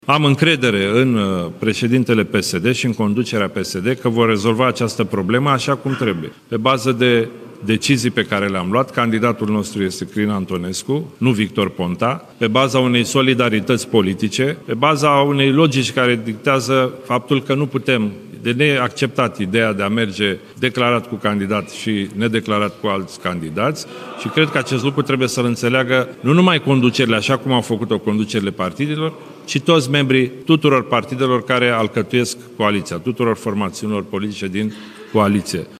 Președintele interimar al PNL, Cătălin Predoiu: „Pe bază de decizii pe care le-am luat, candidatul nostru este Crin Antonescu, nu Victor Ponta”